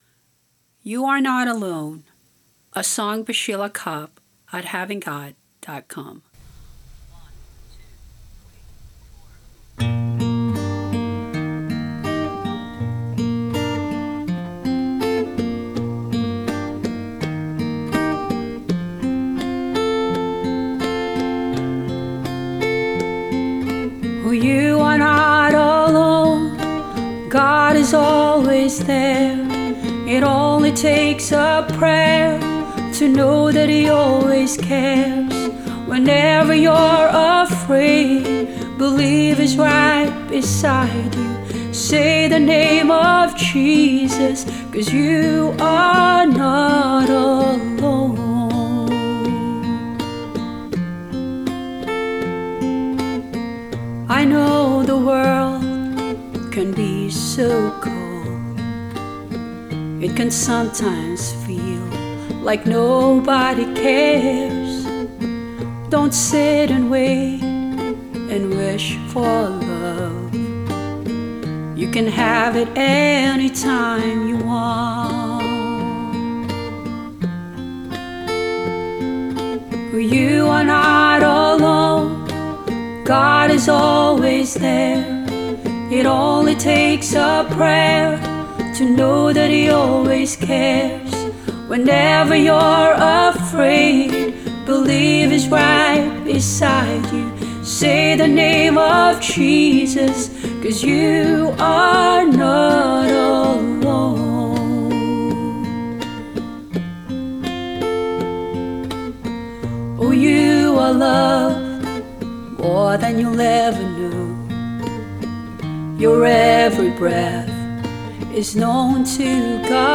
Voices, guitar and bongos